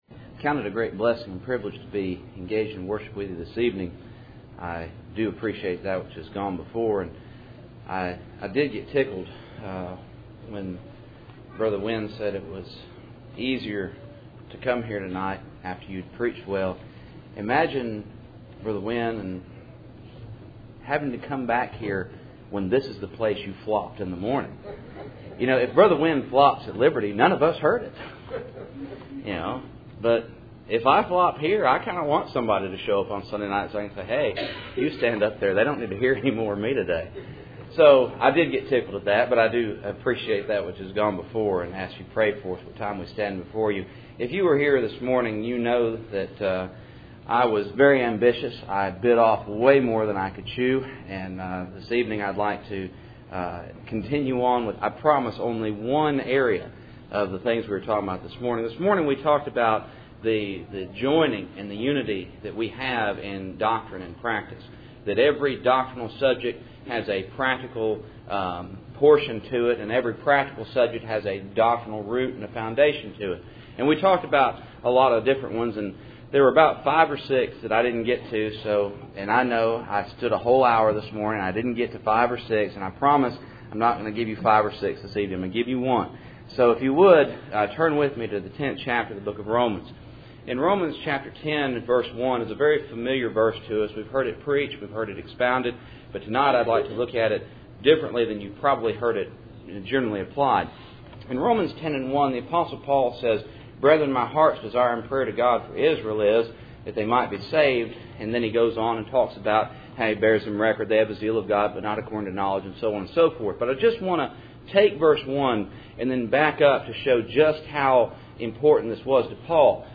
Romans 10:1 Service Type: Cool Springs PBC Sunday Evening %todo_render% « Doctrine and Practice